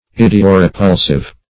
Search Result for " idiorepulsive" : The Collaborative International Dictionary of English v.0.48: Idiorepulsive \Id`i*o*re*pul"sive\, a. [Idio- + repulsive.]